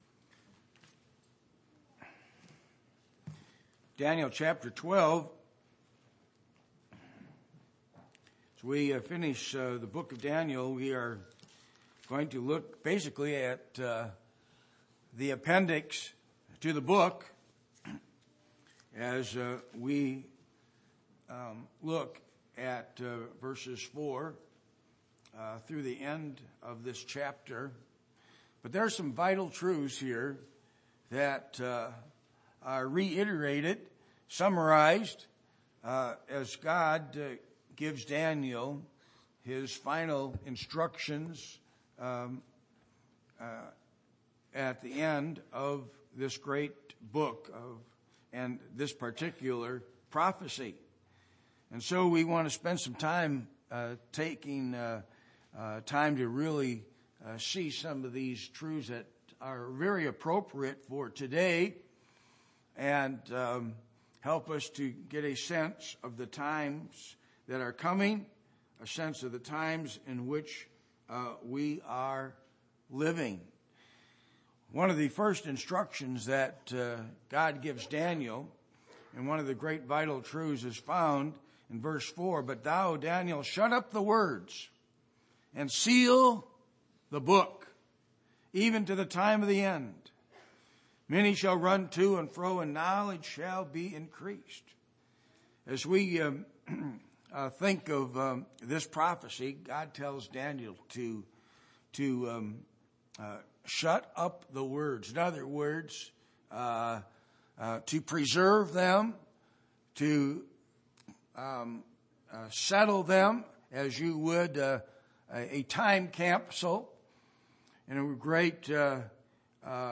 Passage: Daniel 12:1-13 Service Type: Sunday Morning %todo_render% « Have You Forgotten God?